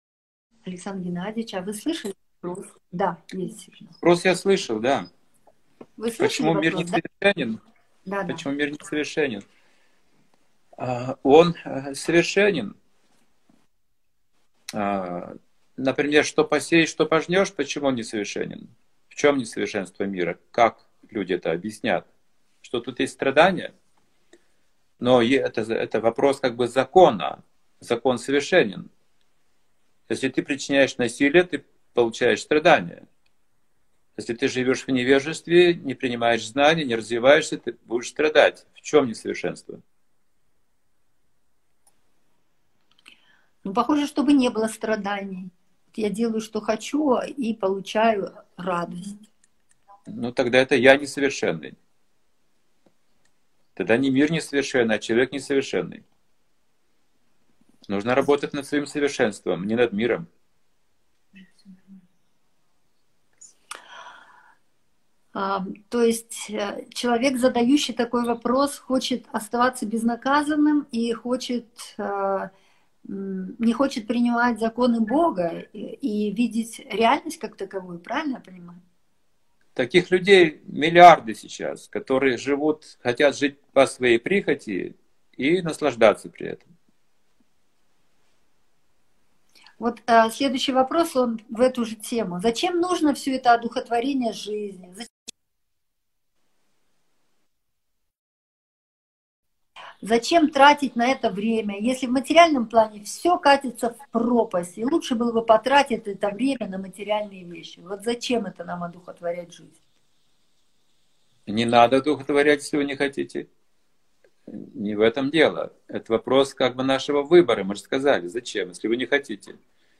Алматы, Беседа